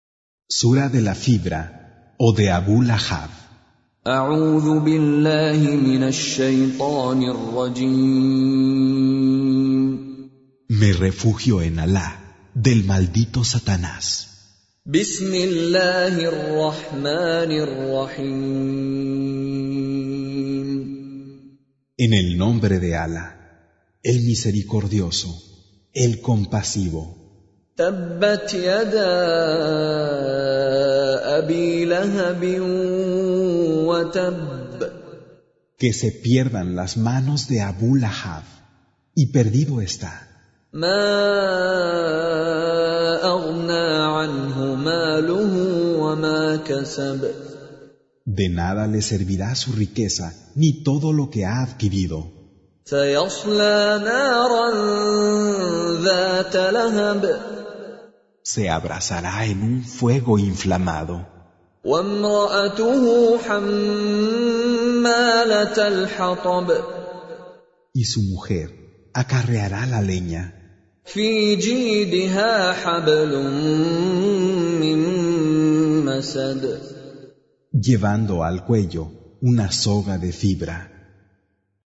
Recitation
Traducción al español del Sagrado Corán - Con Reciter Mishary Alafasi